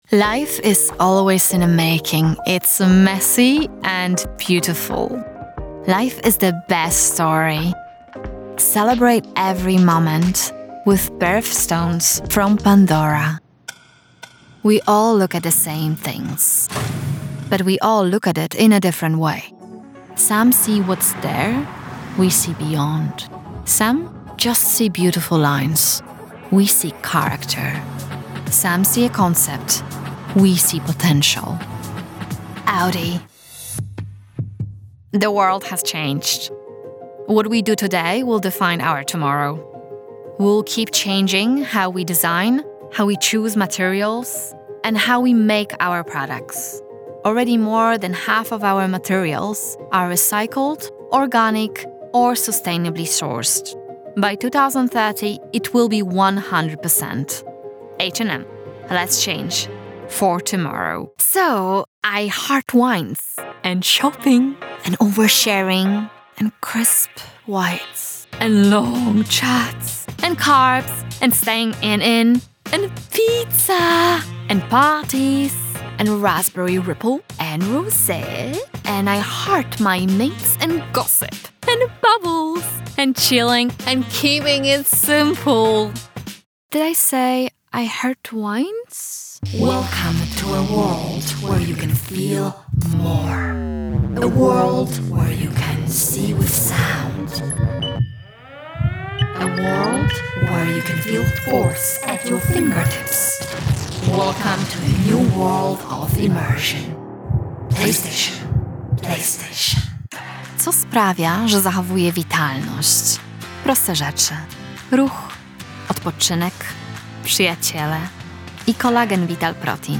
Clear, Bright, Youthful